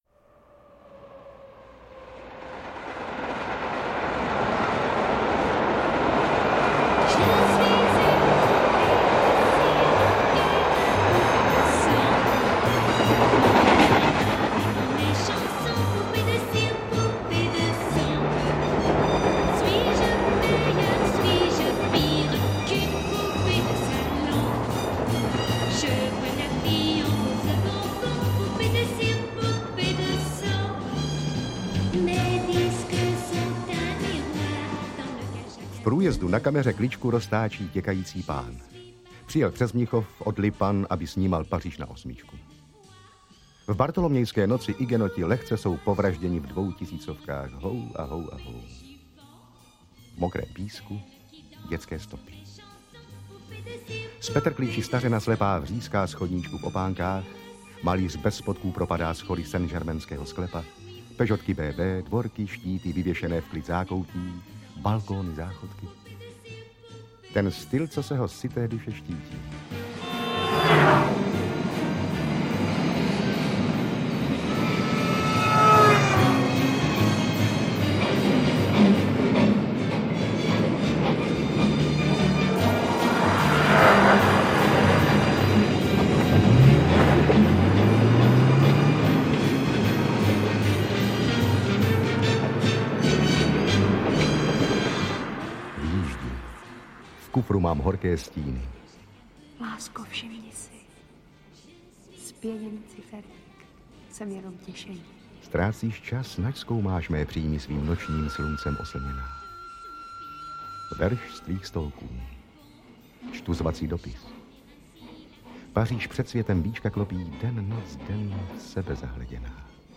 Interpret:  Eduard Cupák
AudioKniha ke stažení, 2 x mp3, délka 40 min., velikost 36,6 MB, česky